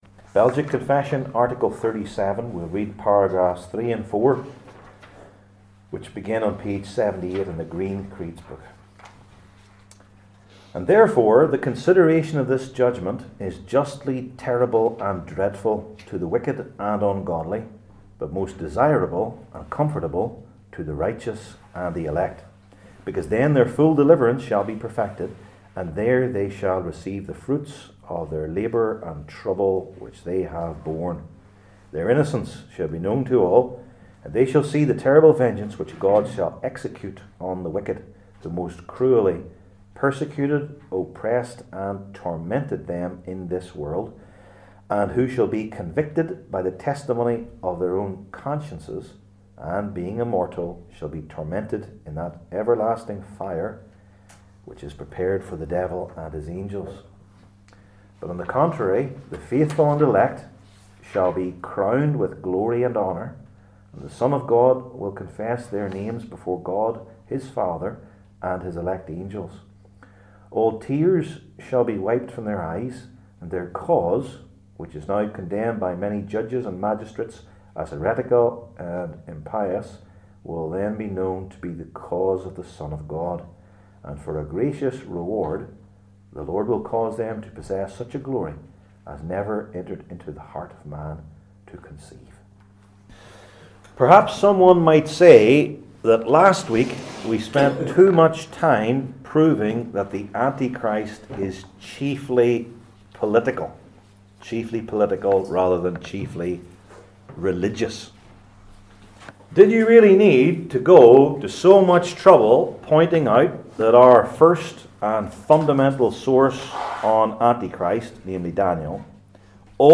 Revelation 13:11-18 Service Type: Belgic Confession Classes THE LAST JUDGMENT …